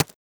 default_ice_dig.3.ogg